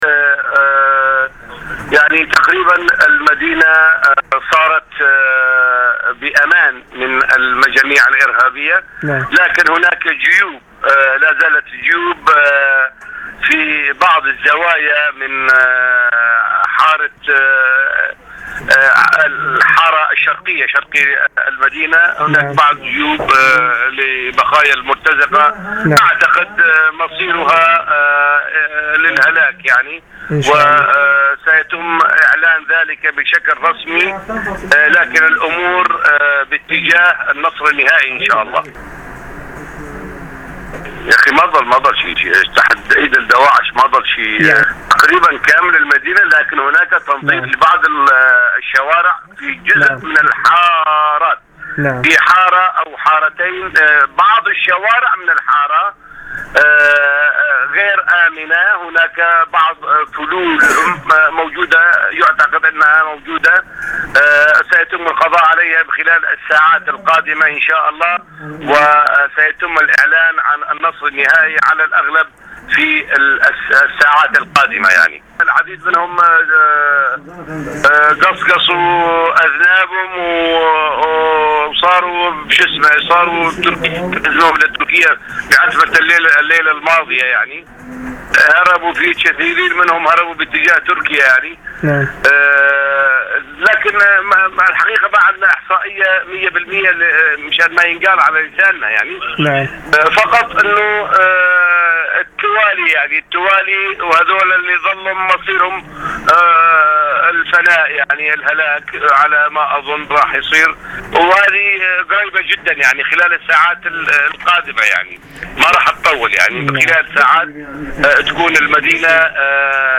"فرحان حاج عیسی" عضو شورای رهبری منطقه کوبانی در مصاحبه اختصاصی با خبرنگار بین‌الملل خبرگزاری تسنیم از آزادی کامل منطقه عین العرب و فرار تروریست‌ها به ترکیه خبر داد.